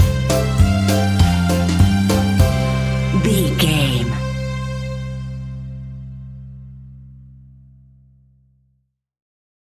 Ionian/Major
instrumentals
childlike
happy
kids piano